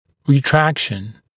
[rɪ’trækʃn][ри’трэкшн]ретракция (как правило, корпусное перемещение зуба кзади или в дистальном направлении)